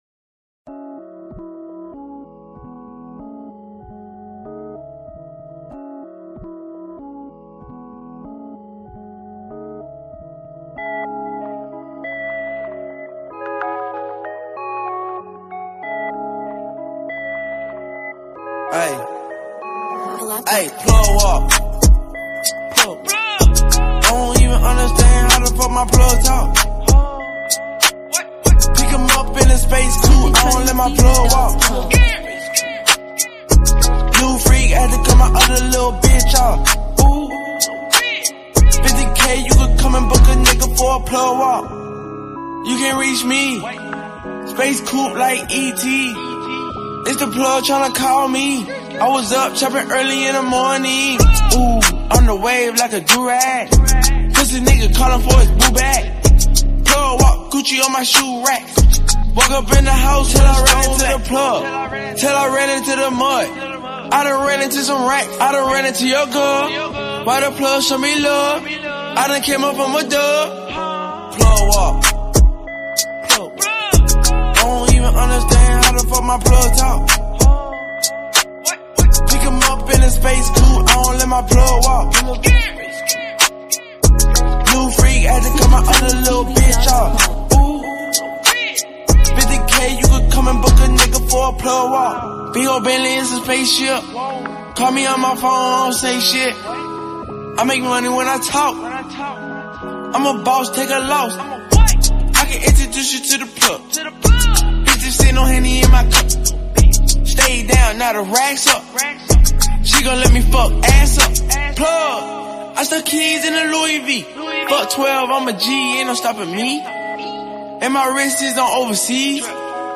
powerful hip hop/rap elements